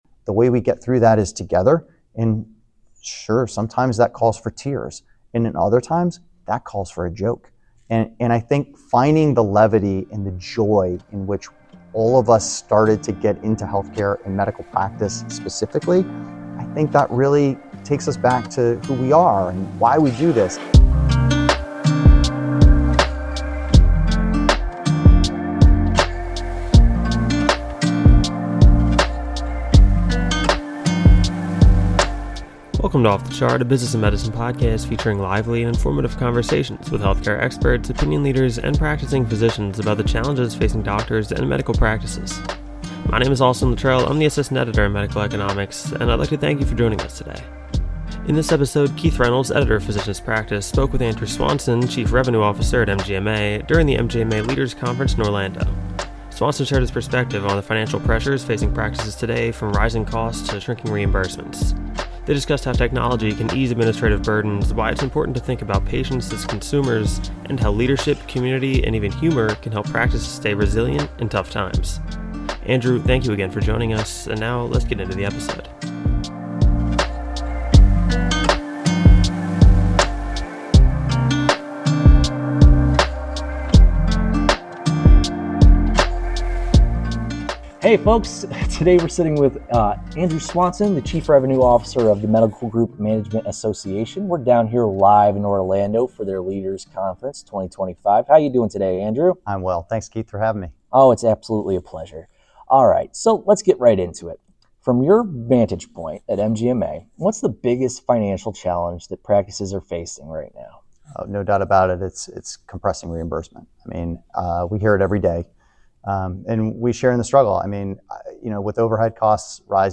Live Conference Introduction (00:01:28)